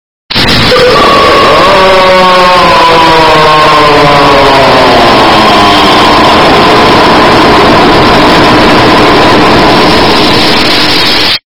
Áudio do Plankton gemendo (Completo) – Estourado
Categoria: Sons virais
Descrição: O som icônico do Plankton gemendo "aaaaaaaugh" em sua versão completa e estourada!
Com volume e bass turbinados, esse áudio é a pedida certa pra quem quer dar aquele toque épico nas trollagens.
audio-do-plankton-gemendo-completo-estourado-pt-www_tiengdong_com.mp3